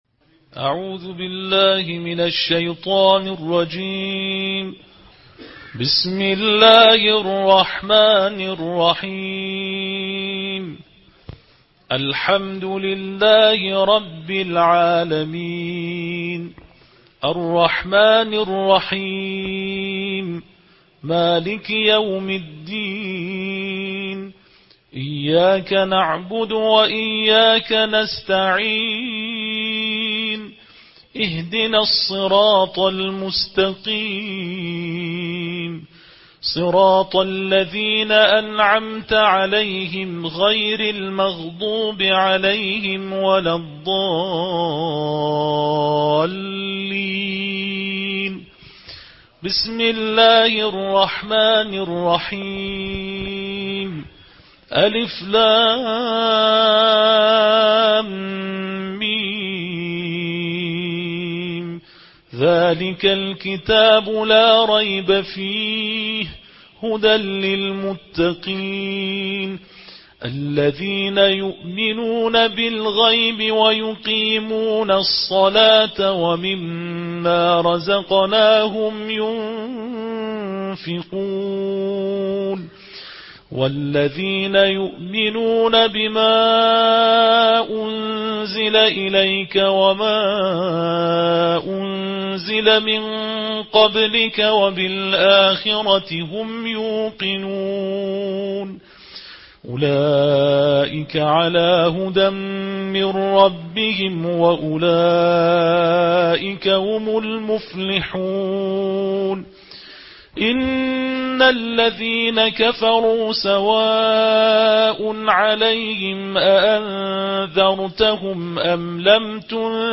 Ежедневное чтение Корана: Тартиль джуза 1